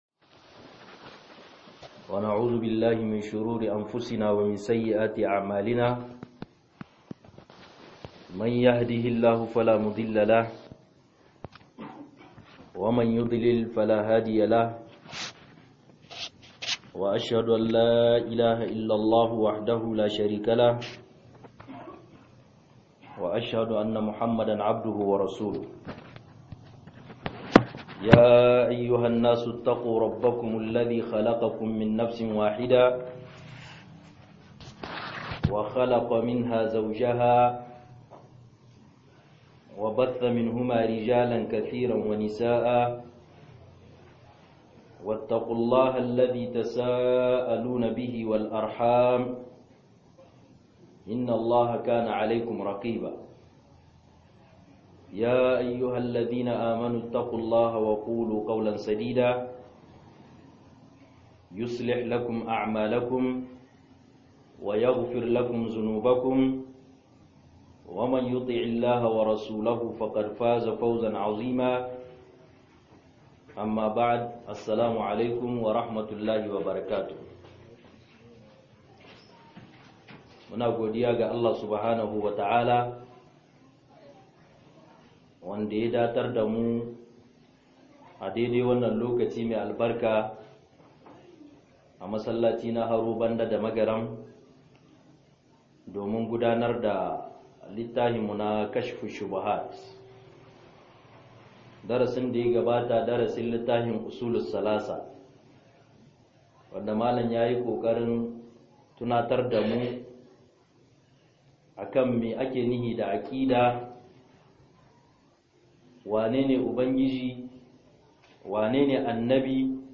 KASHF-SHUBHAT-01 - MUHADARA